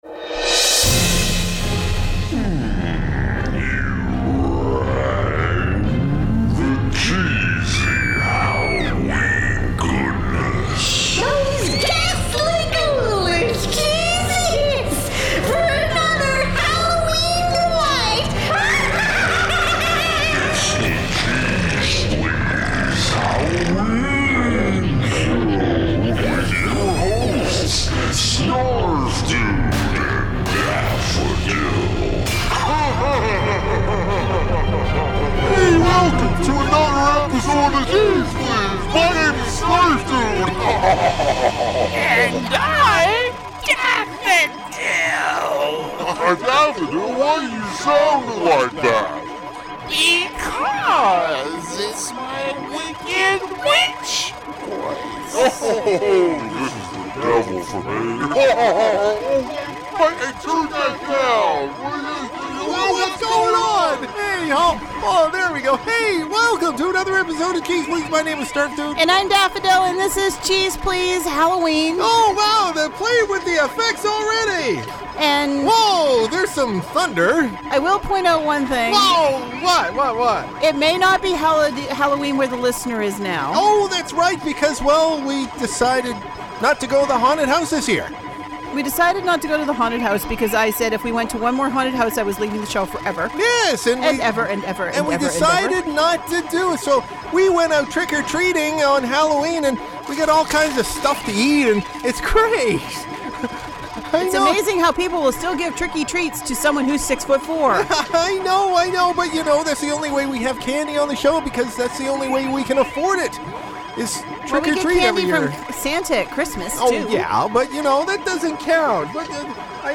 This year's HALLOWEEN SHOW has our hosts chillin in the van....